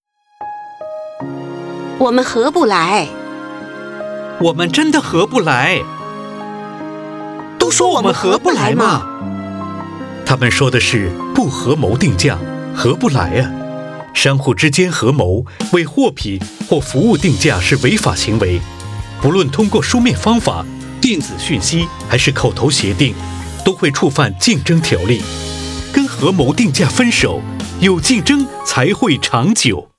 电台宣传声带